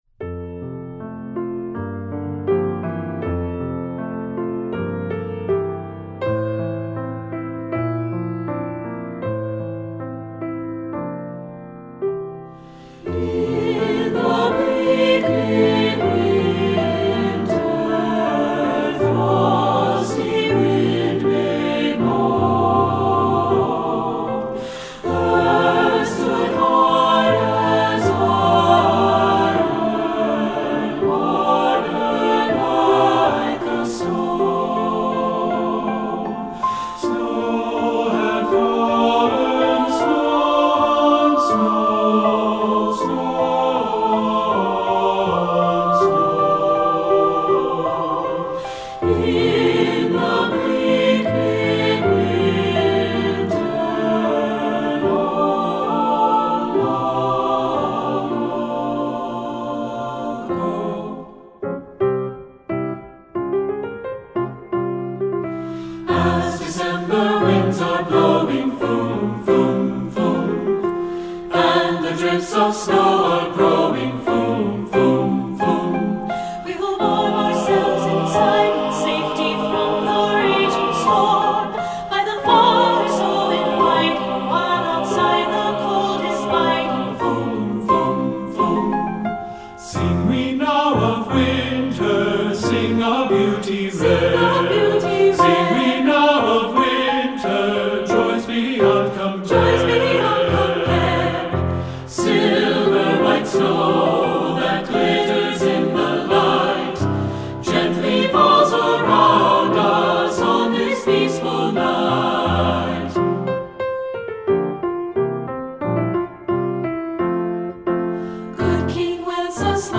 Composer: Traditional Carols
Voicing: SAB